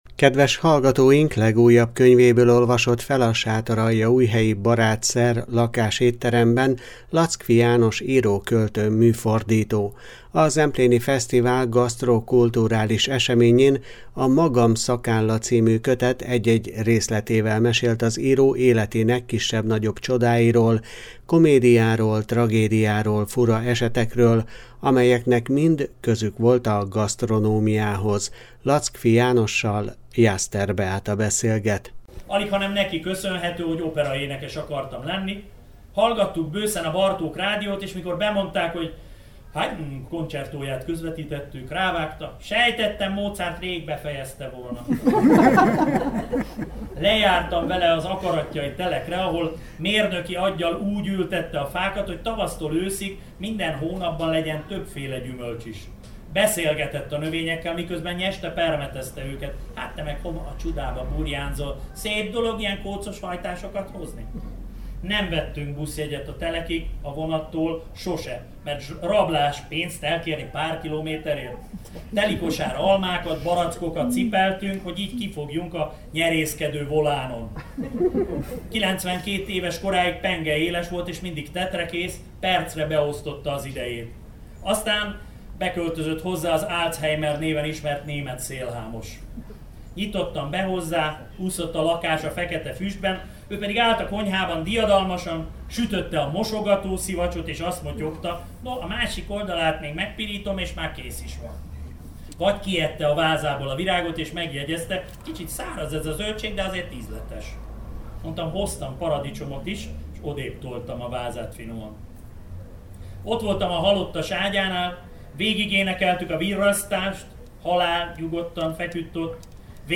Legújabb könyvéből olvasott fel a sátoraljaújhelyi Barátszer Lakásétteremben Lackfi János író, költő, műfordító. A Zempléni Fesztivál gasztro- kulturális eseményén A magam szakálla című kötet egy-egy részletével mesélt az író életének kisebb-nagyobb csodáiról, komédiáról, tragédiáról, fura esetekről, amelyeknek mind közük volt a gasztronómiához.